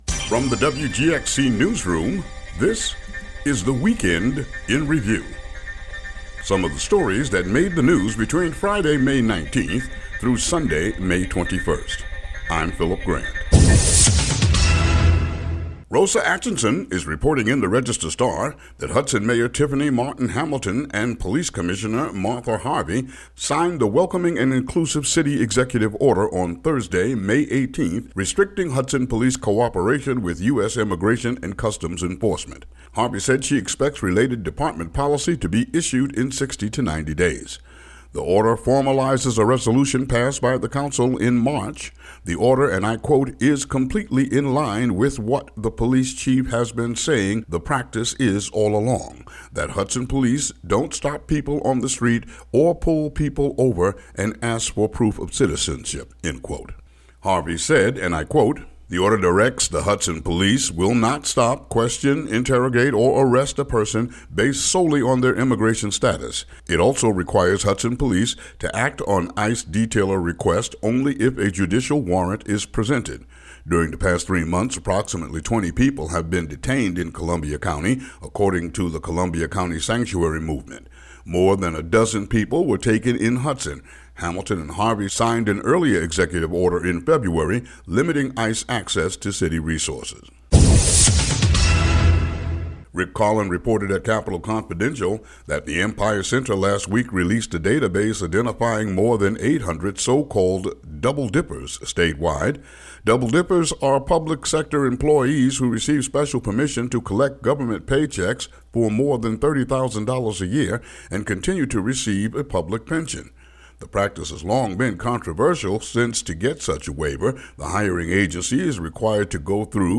WGXC daily headlines for May 22, 2017.